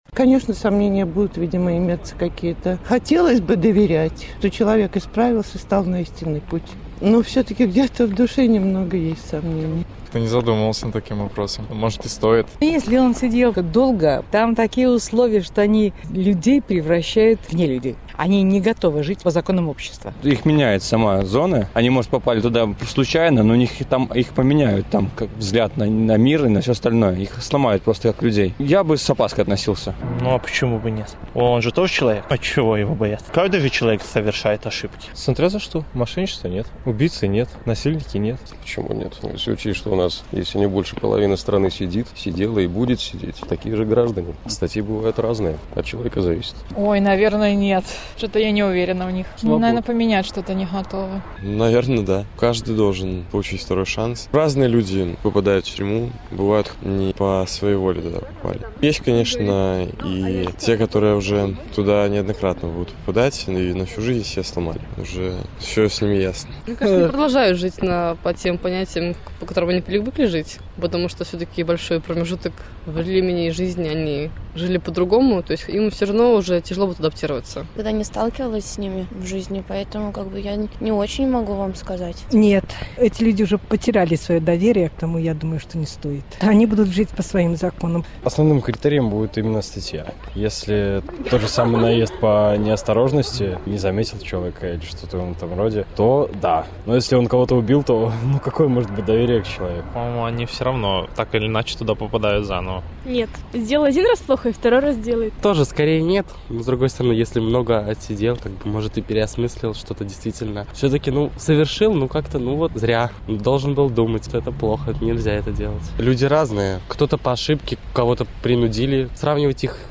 Жыхары Магілёва пра магчымыя адпачынкі для зьняволеных